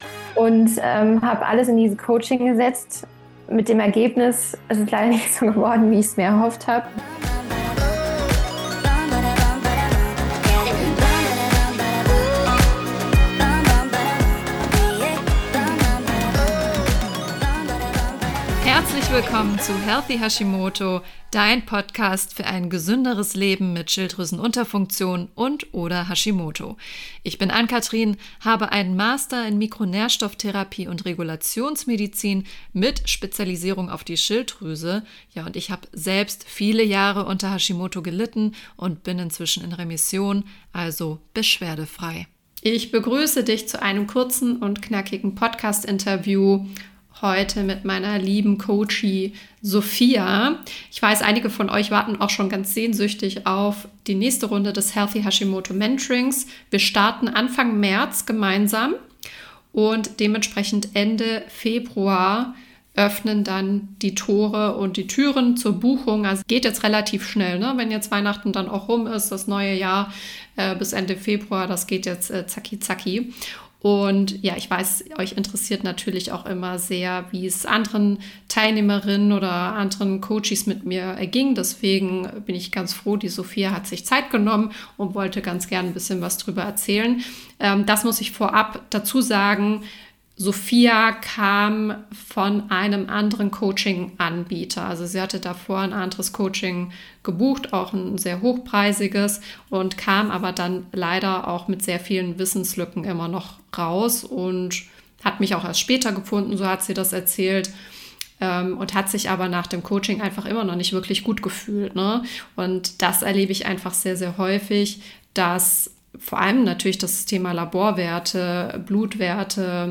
Wie es ihr heute geht, erzählt sie im heutigen Interview.